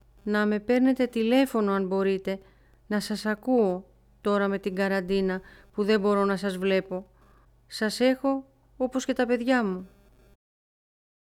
Ηλικιωμένος 7